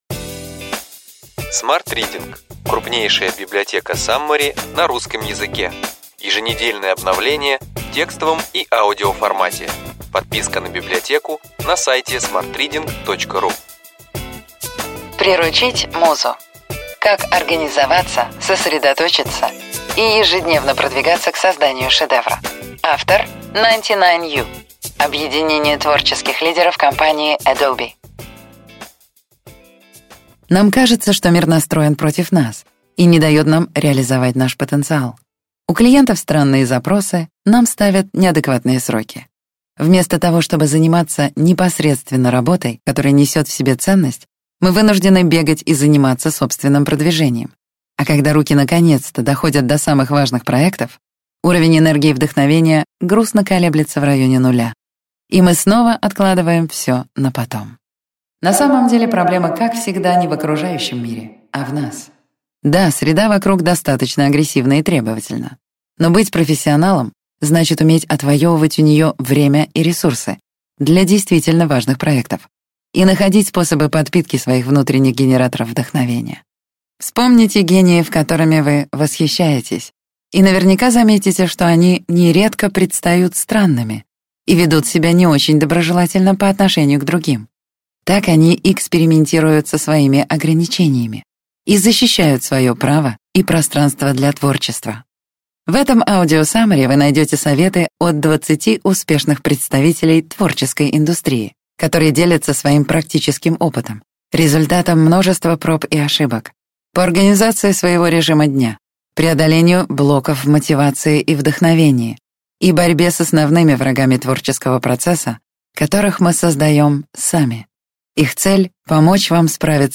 Аудиокнига Ключевые идеи книги: Приручить музу. Как организоваться, сосредоточиться и ежедневно продвигаться к созданию шедевра. 99U | Библиотека аудиокниг